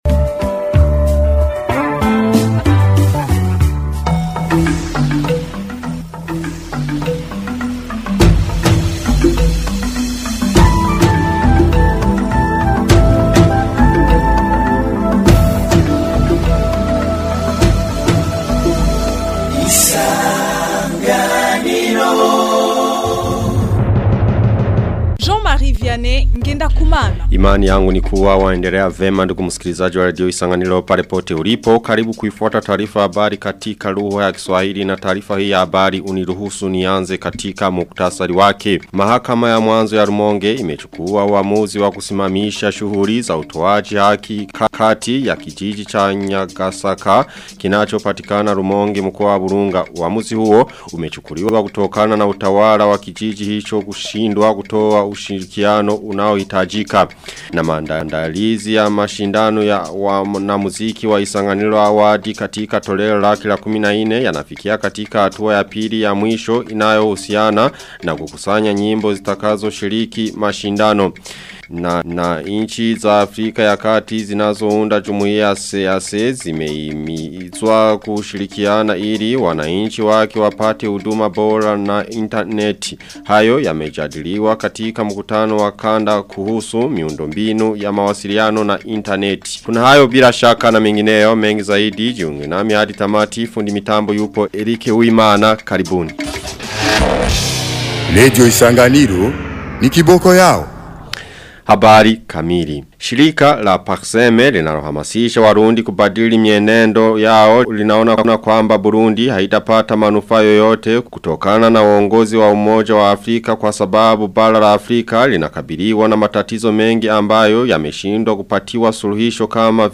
Taarifa ya habari ya tarehe 19 Februari 2026